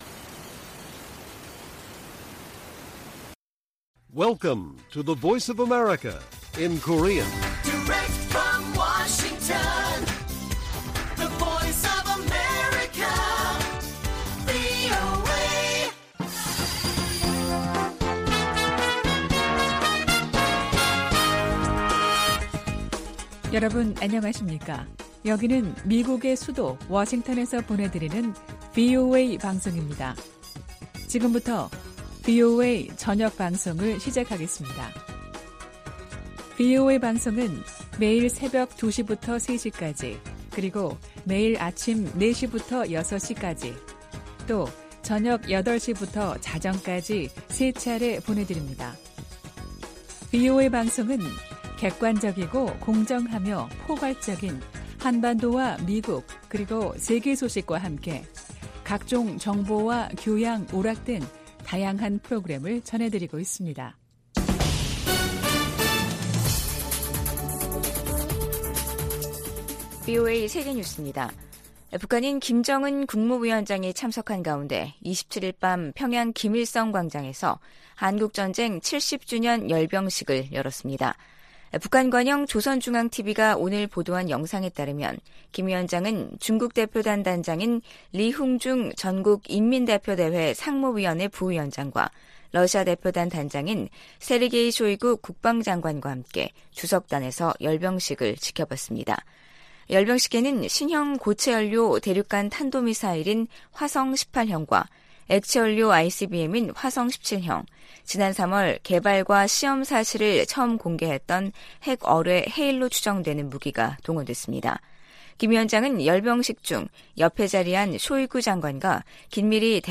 VOA 한국어 간판 뉴스 프로그램 '뉴스 투데이', 2023년 7월 28일 1부 방송입니다. 미 국무부는 러시아가 북한의 불법 무기 프로그램을 지원하고 있다고 비난했습니다. 로이드 오스틴 미 국방장관은 미한 상호방위조약이 체결된 지 70년이 지난 지금 동맹은 어느 때보다 강력하다고 밝혔습니다. 북한이 김정은 국무위원장이 참석한 가운데 '전승절' 열병식을 열고 대륙간탄도미사일 등 핵 무력을 과시했습니다.